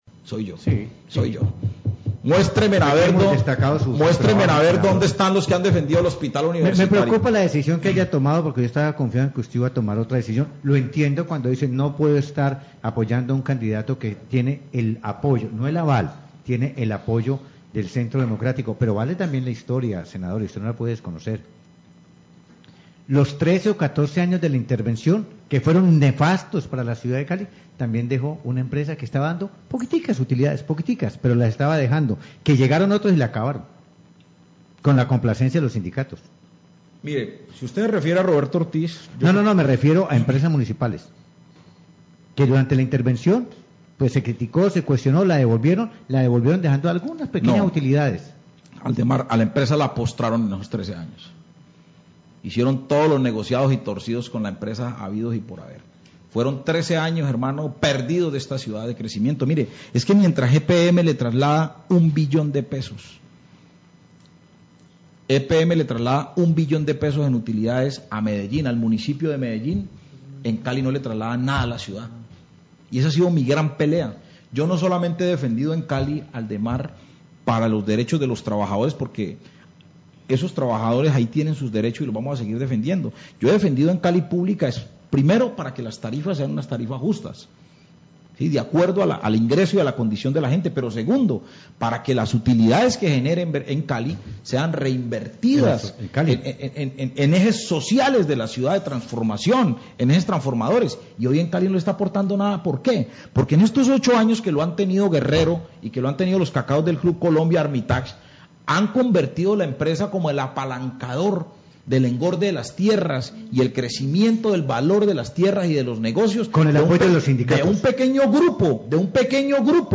Radio
Senador López habla sobre cómo quedó la empresa después de la intervención.  Señala que los últimos alcaldes se tomaron las empresas municipales para satisfacer intereses personales.